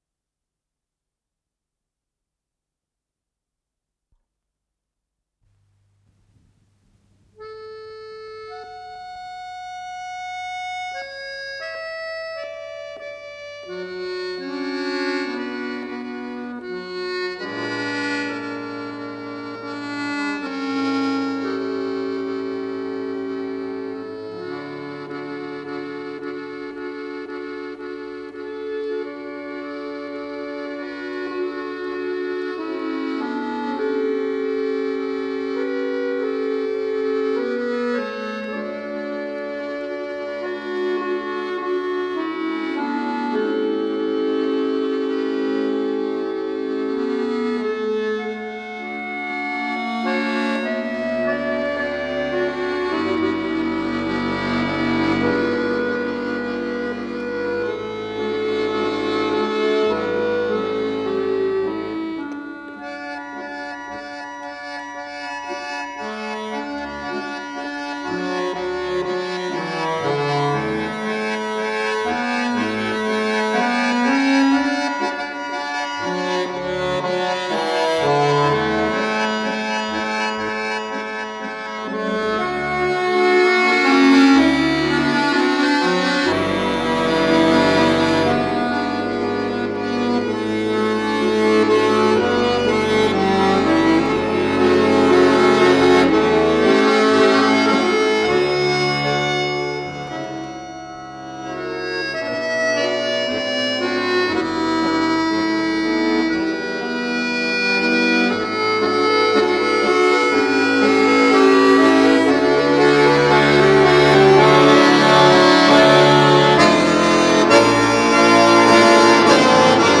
с профессиональной магнитной ленты
КомпозиторыРусская народная
Скорость ленты38 см/с